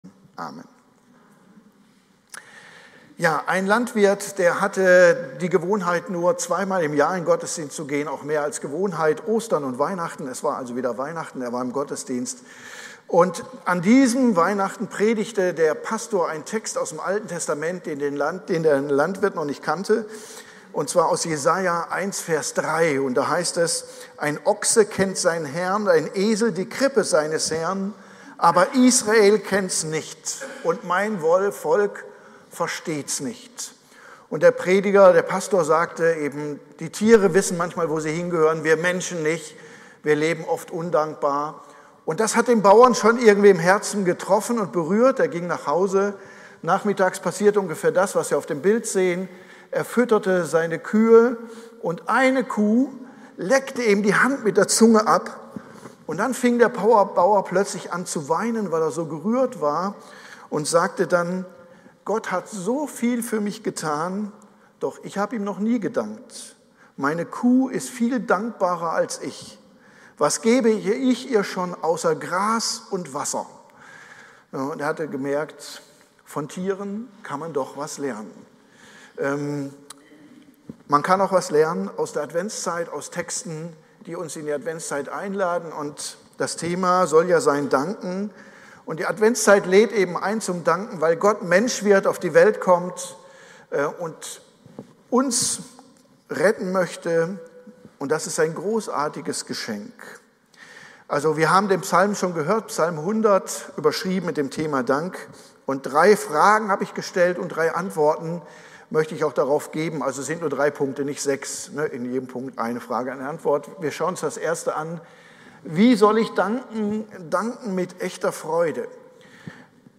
Predigt-am-08.12-online-audio-converter.com_.mp3